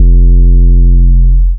DDW2 808 6.wav